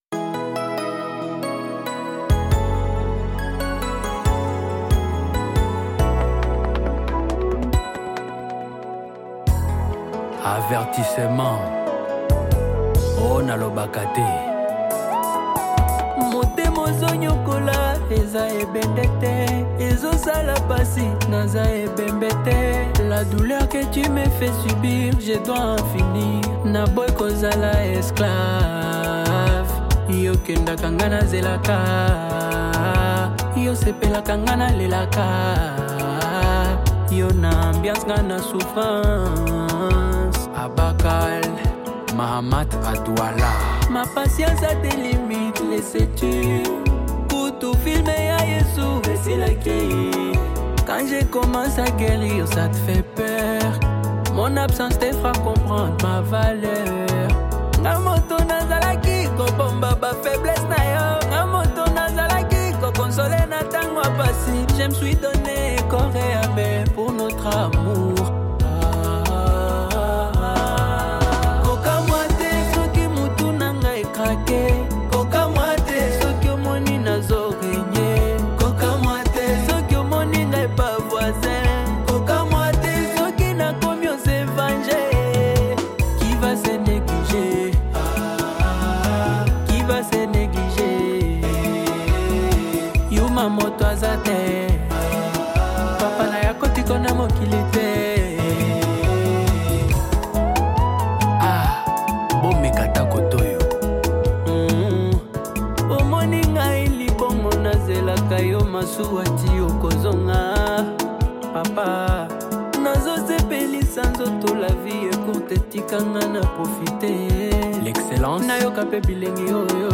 compelling Afro-urban single
where he blends energetic melodies with sharp
Genre: Rhumba